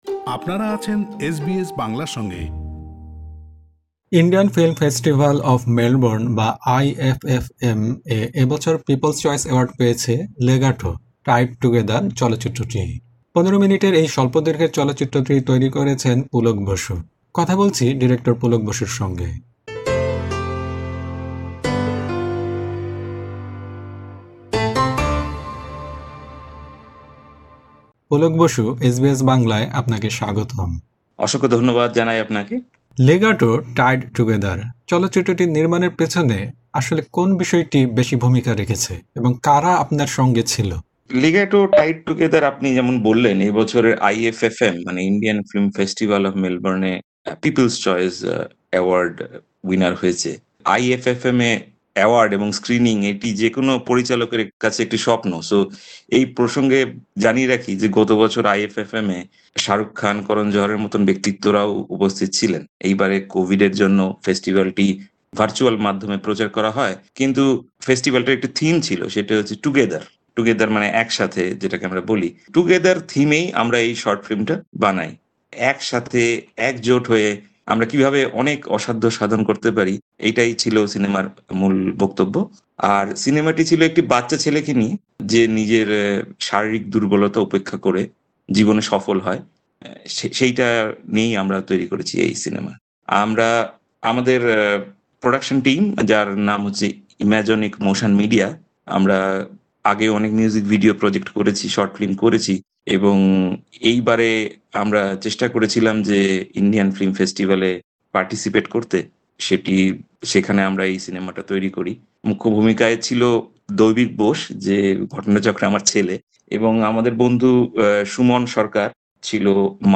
এসবিএস বাংলা